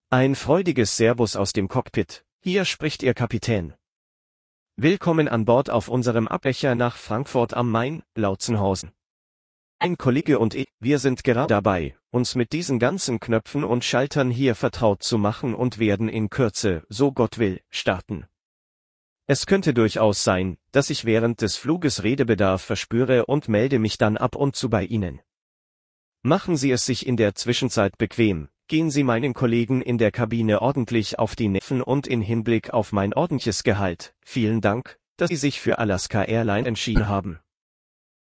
BoardingWelcomePilot.ogg